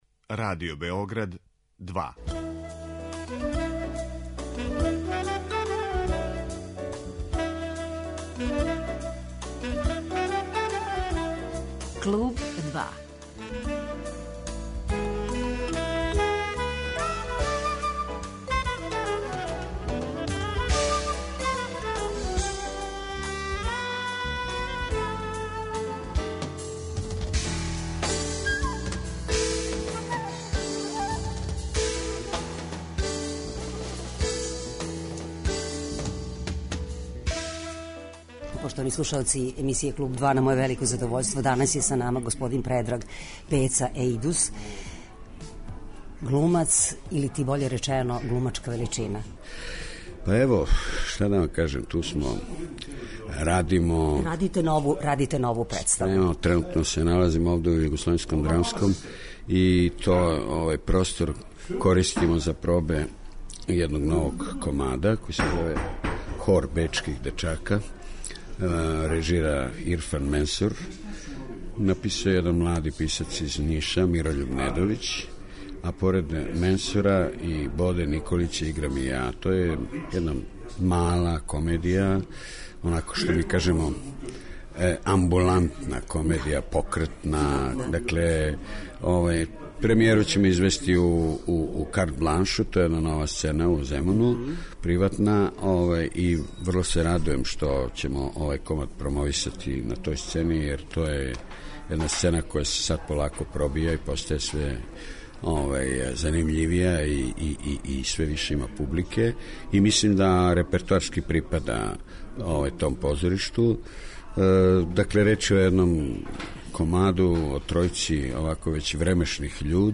Гост данашње емисије је драмски уметник Предраг Ејдус, а повод је нова позоришна улога коју припрема.